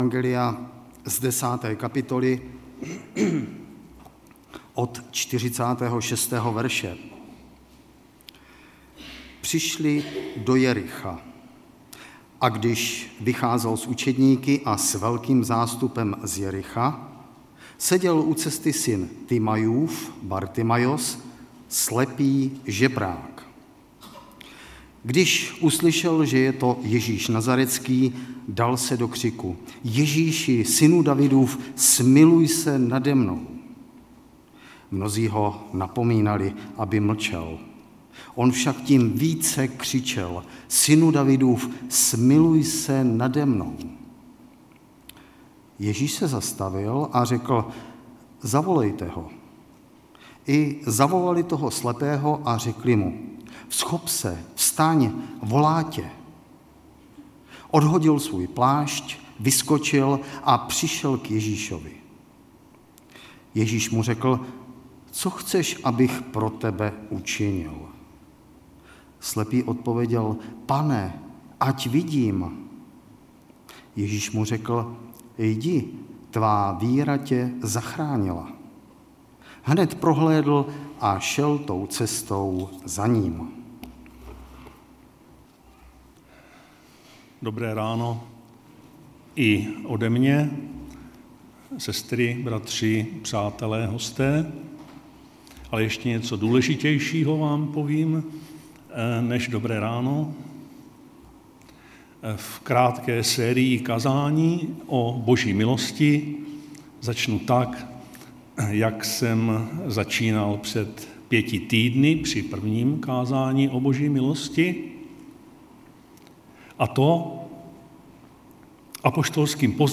V rámci krátké série kázání i dnes přijměte apoštolský pozdrav Ef 1,2: Milost vám a pokoj od Boha…